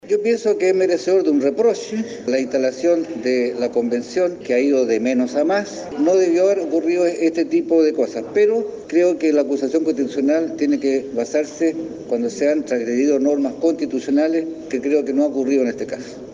El jefe de bancada de la Democracia Cristiana, Daniel Verdessi, dijo que su postura es que el ministro Ossa merece un reproche, pero no necesariamente el comienzo de una acusación constitucional.